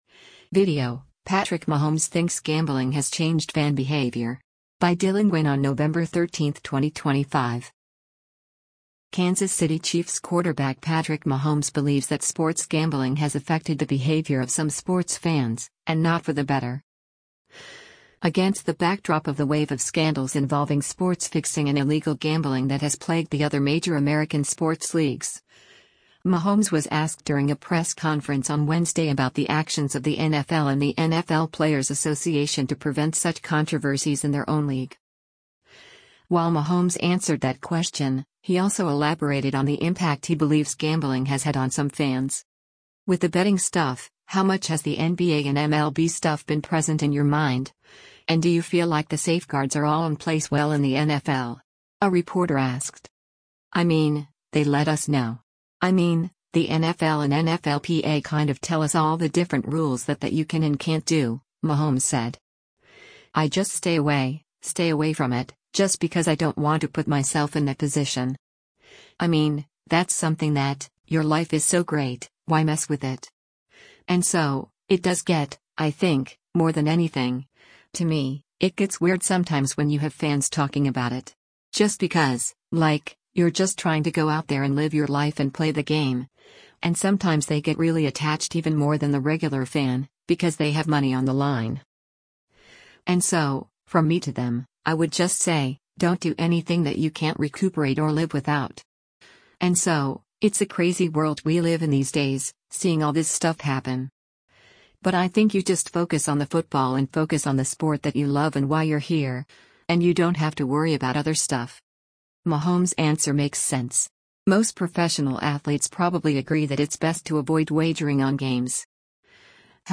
Against the backdrop of the wave of scandals involving sports fixing and illegal gambling that has plagued the other major American sports leagues, Mahomes was asked during a press conference on Wednesday about the actions of the NFL and the NFL Players’ Association to prevent such controversies in their own league.